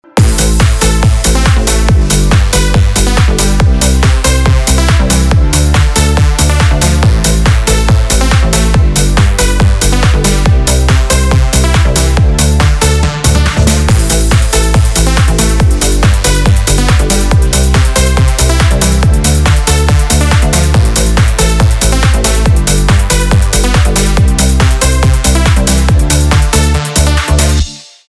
танцевальные
без слов , инструментальные , электроника